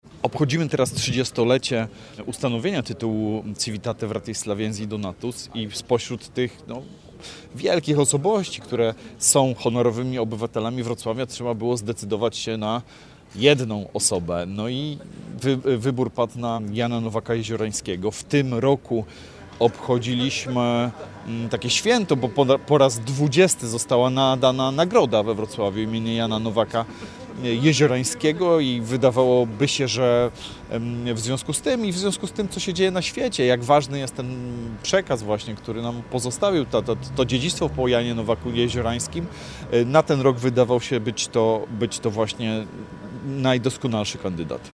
Od 2004 roku w stolicy Dolnego Śląska przyznawana jest Nagroda Jana Nowaka-Jeziorańskiego, mówi Sergiusz Kmiecik – Przewodniczący Rady Miejskiej Wrocławia.